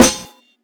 Snares
YYUUU_SNR.wav